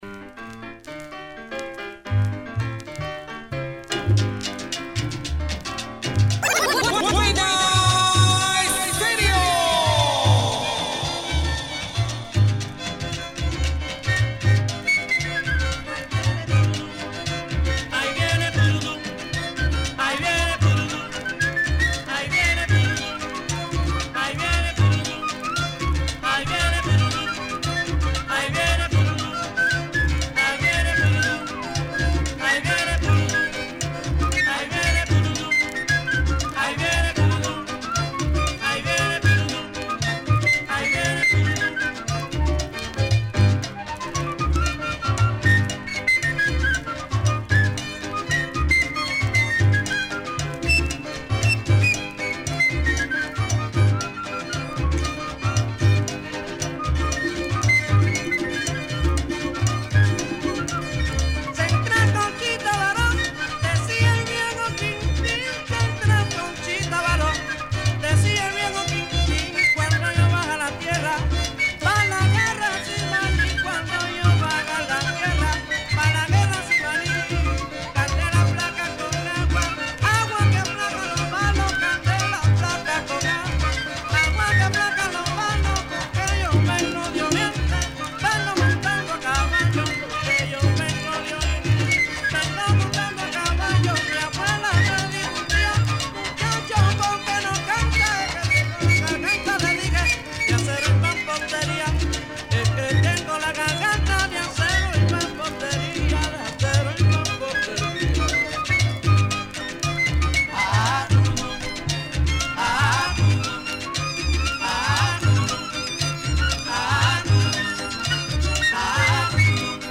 El programa mas bacano para escuchar salsa, champeta, africana y vallenato.